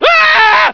almost finished mining laser and lots of screaming scientists
scream25.ogg